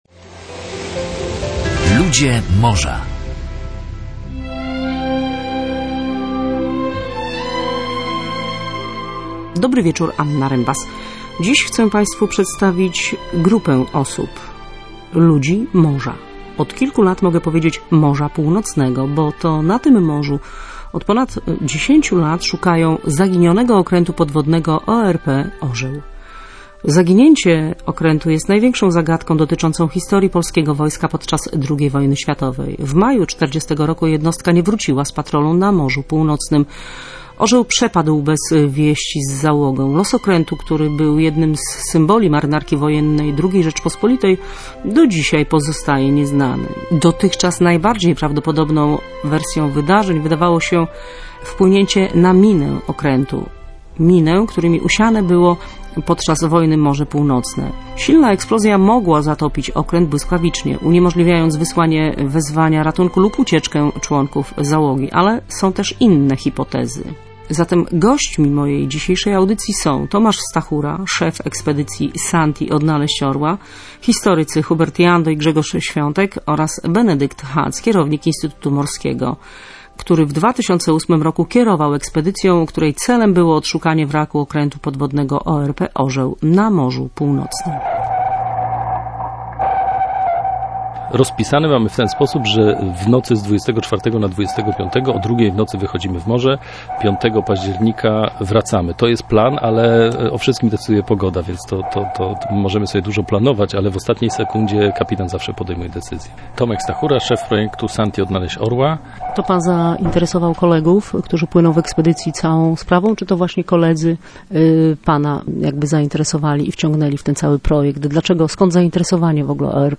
rozmawia z uczestnikami ekspedycji „Santi Odnaleźć Orła”